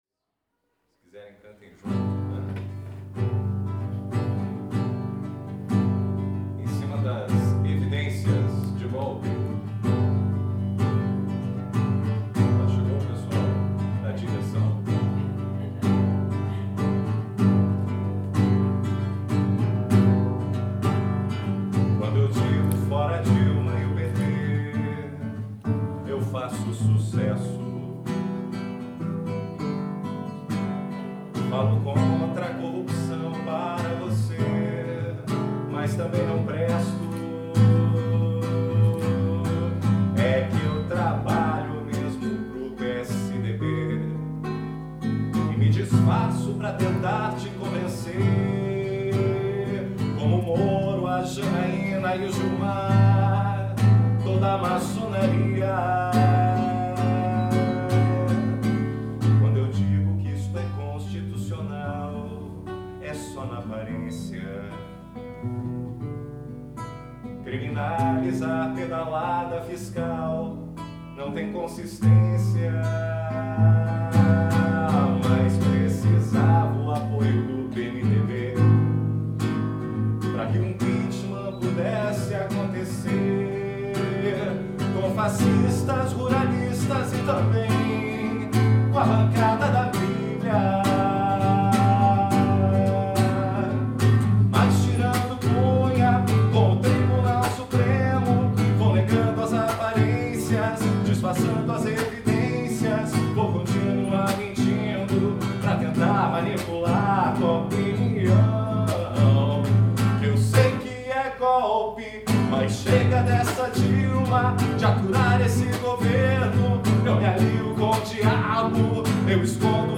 Paródia 04